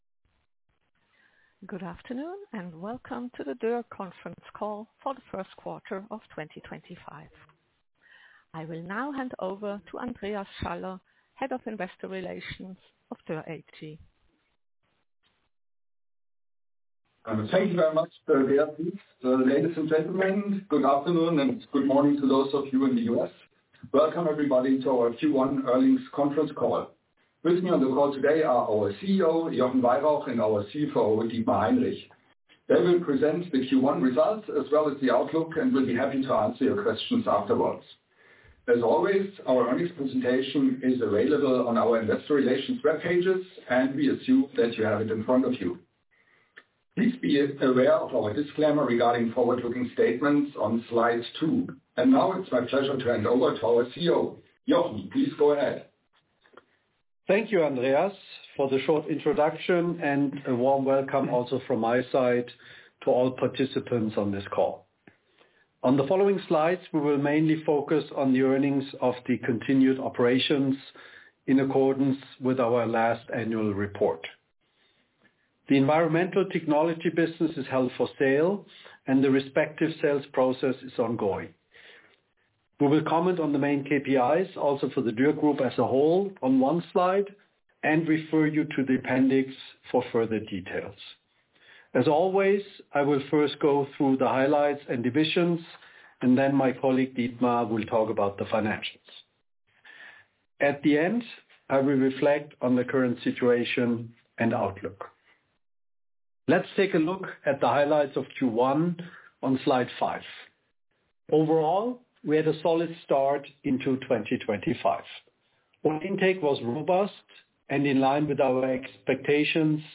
Recording Conference Call Q3 2025
duerr-recording-conference-call-q1-2025.mp3